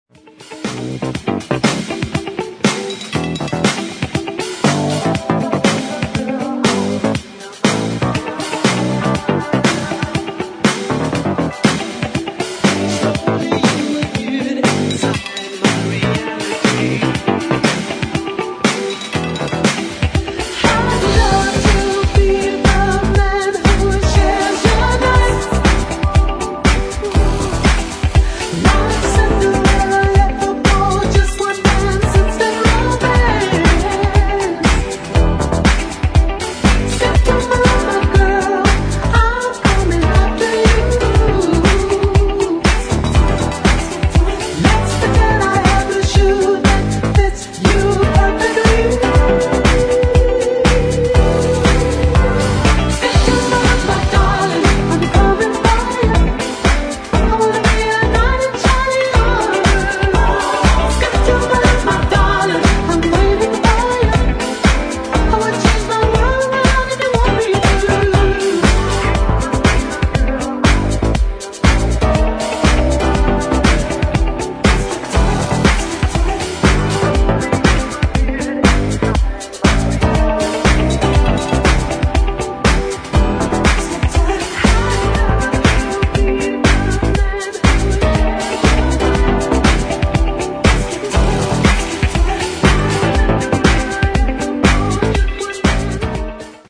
[ DISCO / EDIT ]
80年代ブギー・スタイルの